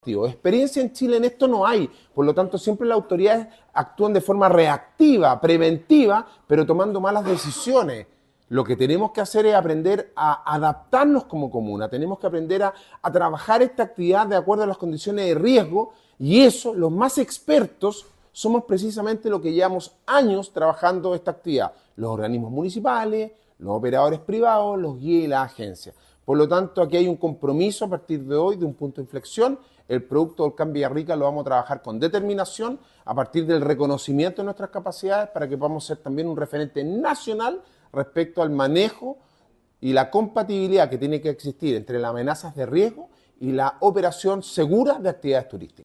Alcalde-Sebastian-Alvarez-explica-el-trabajo-preventivo-y-de-adaptacion.mp3